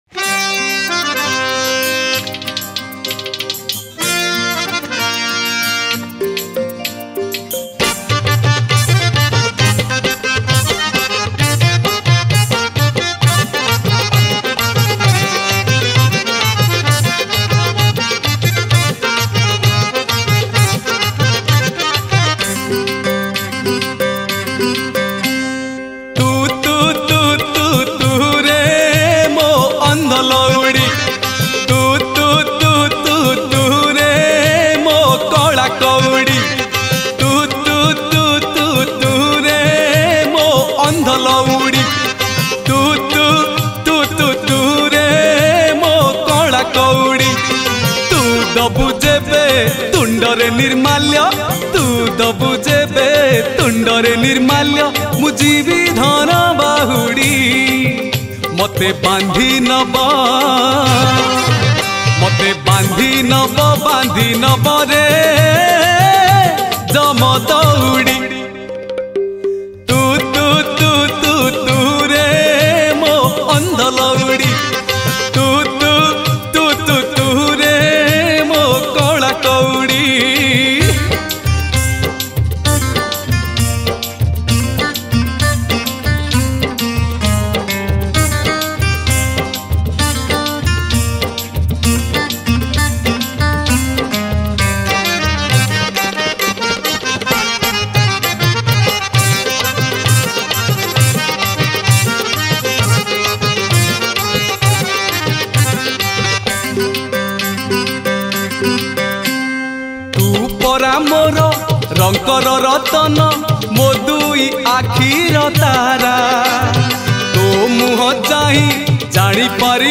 Ratha Yatra Odia Bhajan 2000-21 Songs Download